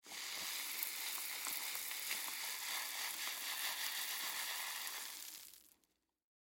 На этой странице собраны разнообразные звуки, связанные с пищевой солью: хруст кристаллов, шум пересыпания, звук растворения в воде и другие.
Шуршание соли при пересыпании из пакета в контейнер